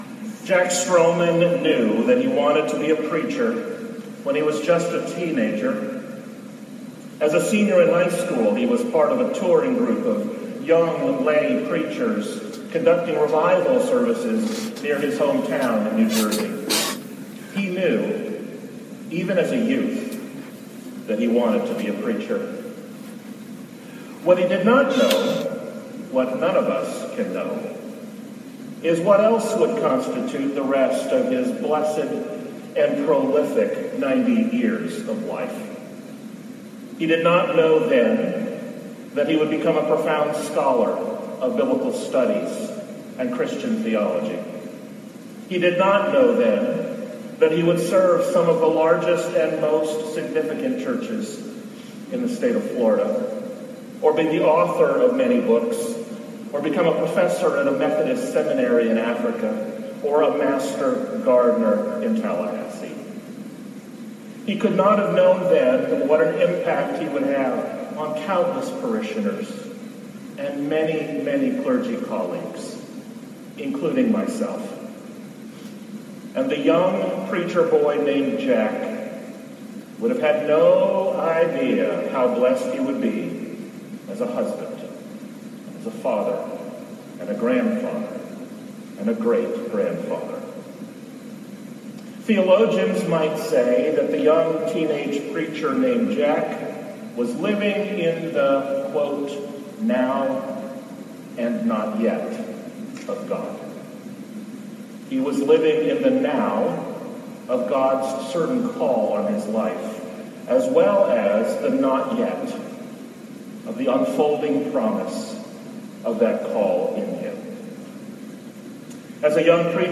Eulogy